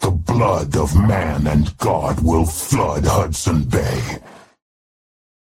Fathom voice line - The blood of man and god will flood Hudson Bay.